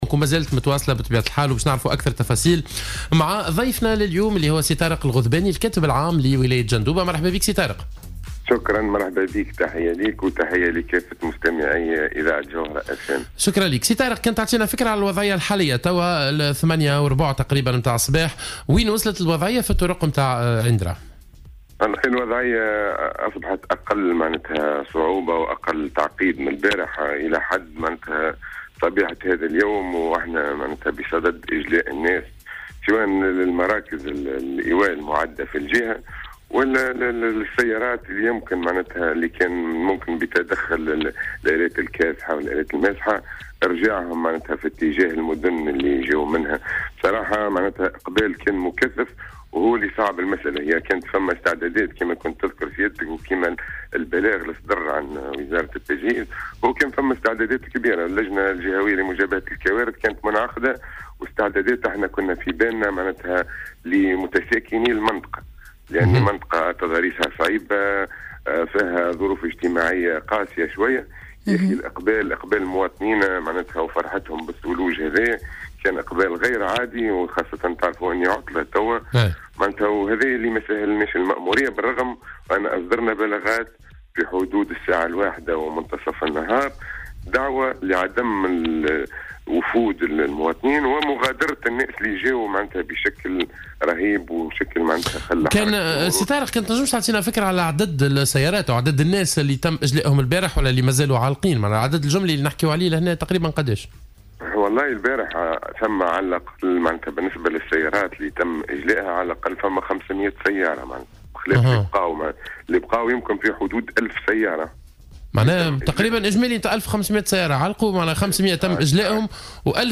وأضاف المسؤول بولاية جندوبة لـ "الجوهرة اف أم" في "صباح الورد" أنه تم إجلاء إلى حد الساعة نحو 500 سيارة كانت عالقة إلا انه لا تزال هناك نحو ألف سيارة أخرى عالقة.